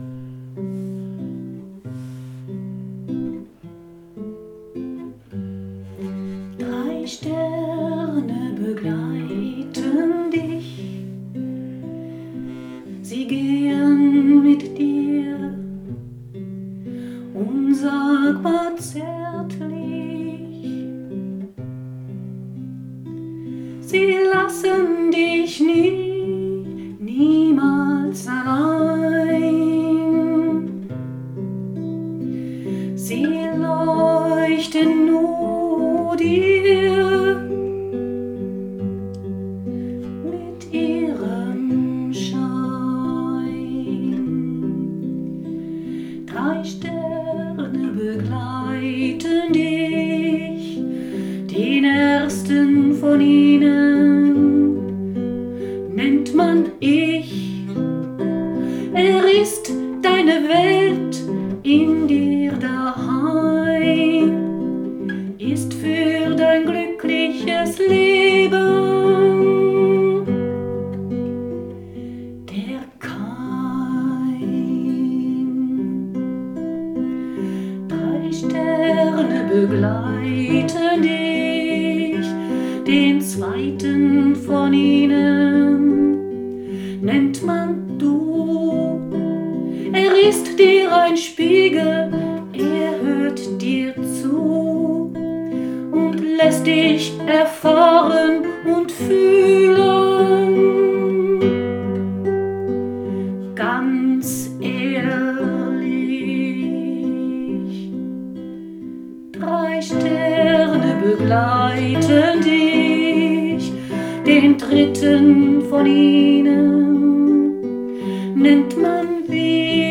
Drei Sterne begleiten dich - Lied